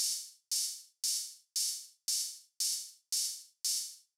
MOO Beat - Mix 13.wav